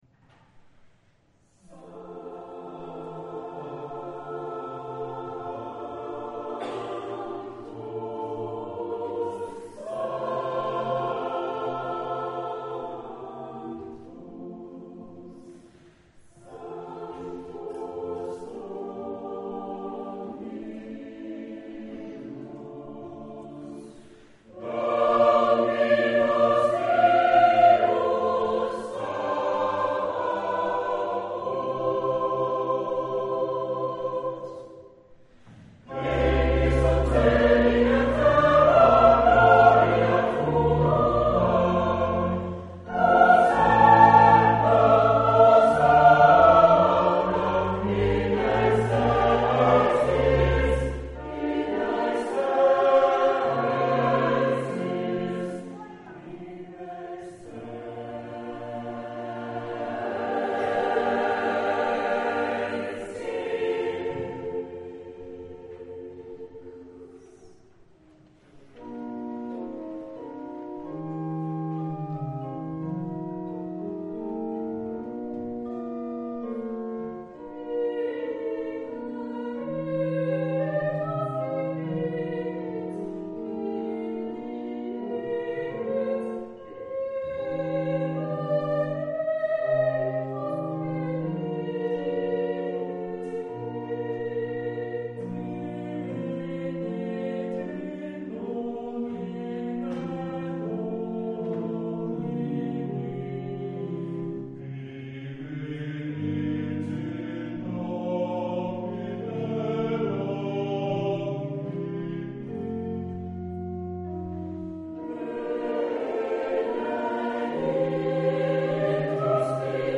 Hochamt - Kirtag 2008